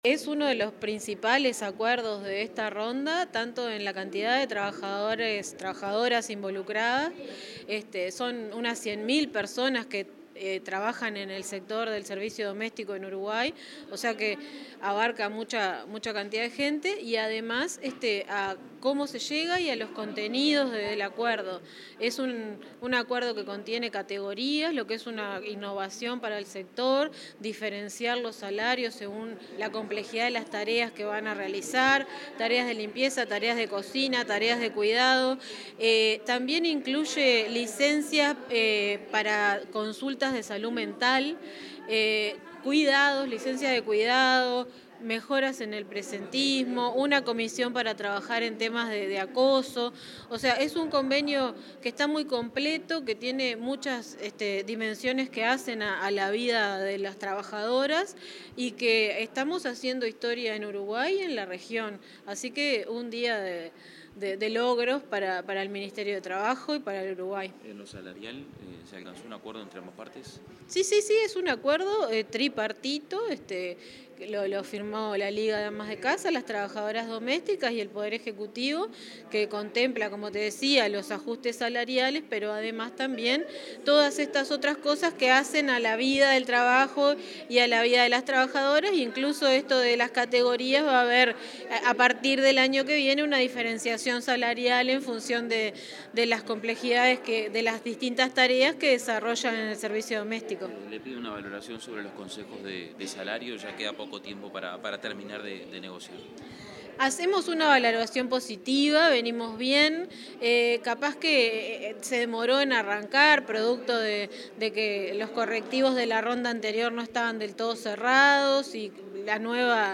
Declaraciones de la directora nacional de Trabajo, Marcela Barrios
Declaraciones de la directora nacional de Trabajo, Marcela Barrios 05/12/2025 Compartir Facebook X Copiar enlace WhatsApp LinkedIn Tras la firma del acuerdo tripartito de negociación colectiva en el Grupo 21: Trabajo Doméstico, la directora nacional de Trabajo del Ministerio de Trabajo y Seguridad Social (MTSS), Marcela Barrios, dialogó con la prensa.
MarcelaBarrios-prensa.mp3